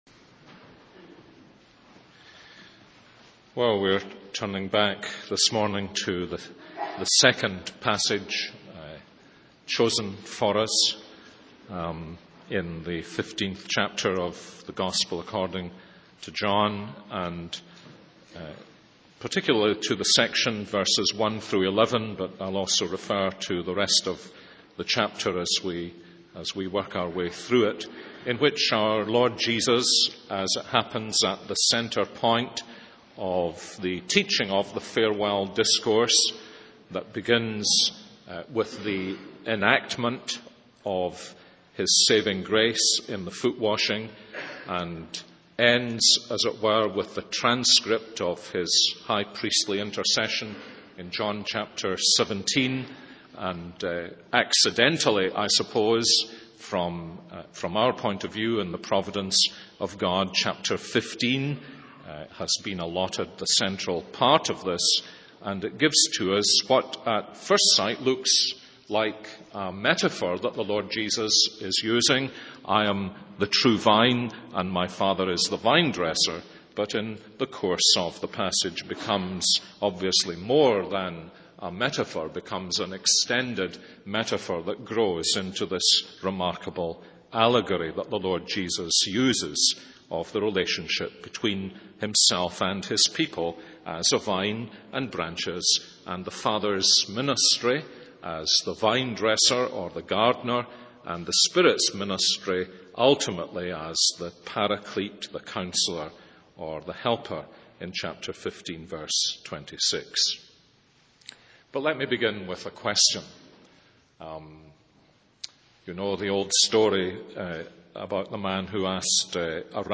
In this sermon, the speaker emphasizes the uniqueness of each individual and the importance of exploring and understanding one another within the church community.